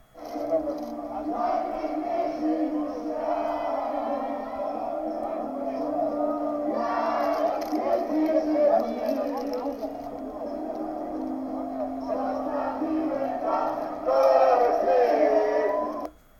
Z magnetofonu ustawionego na chodniku dudni muzyka Snu o Warszawie. Zamiast Niemena o nadwiślańskim świcie śpiewa grupa pijanych mężczyzn. Imprezowicze pokrzykują do siebie.
Tłuką się butelki.